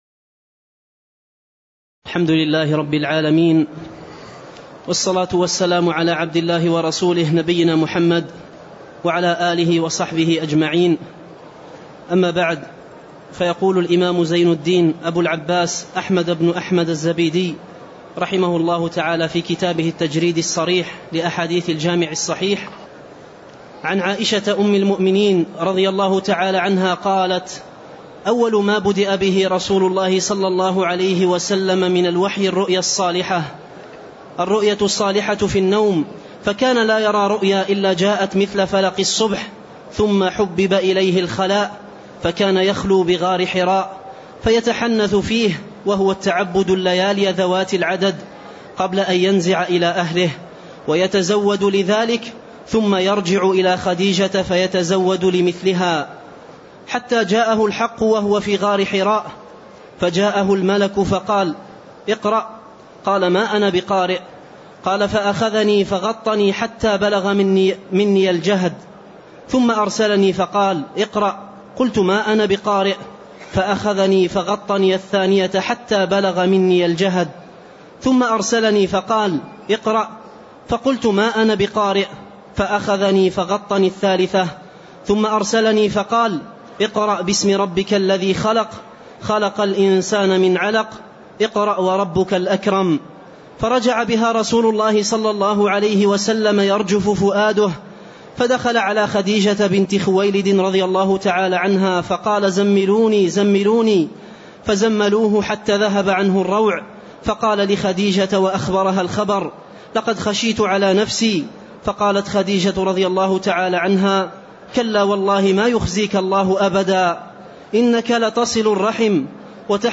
تاريخ النشر ٦ ربيع الثاني ١٤٣٣ هـ المكان: المسجد النبوي الشيخ